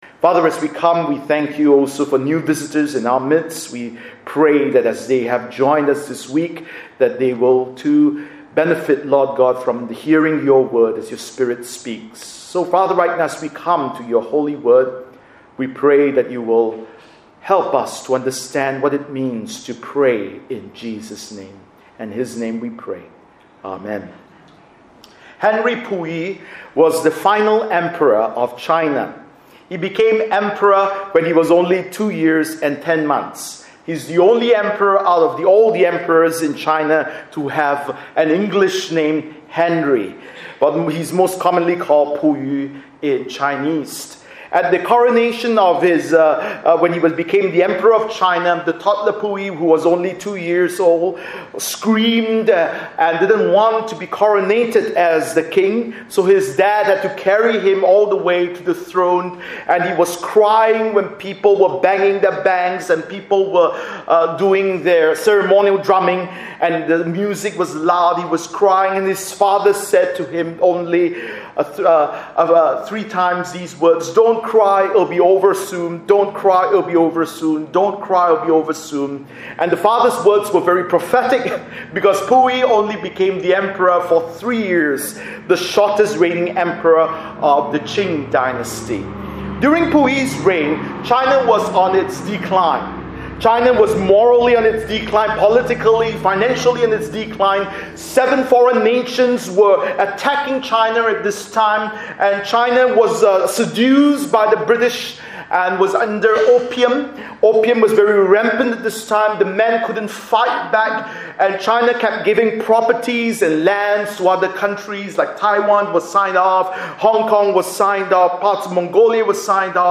Bible Text: John 16:16-33 | Preacher